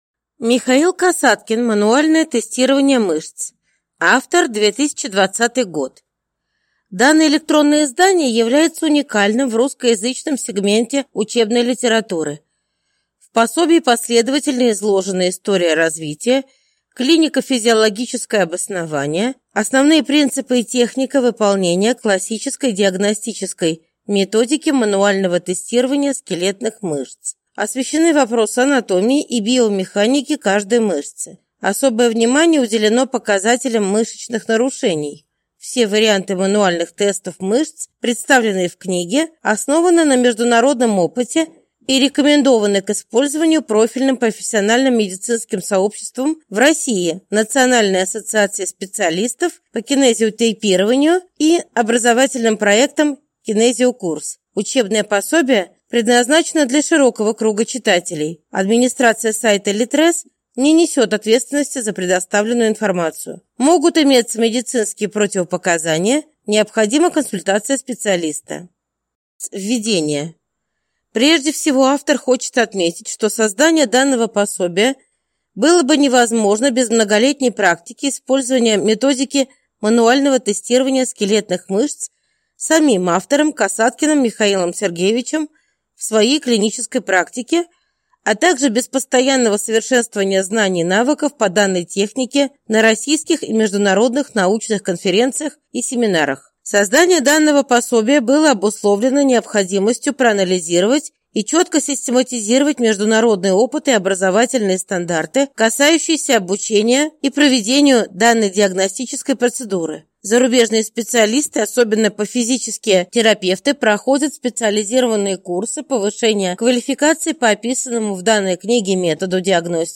Аудиокнига Мануальное тестирование мышц | Библиотека аудиокниг